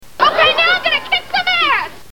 Category: Television   Right: Personal
Tags: Friends Comedy Television Phoebe Jennifer Aniston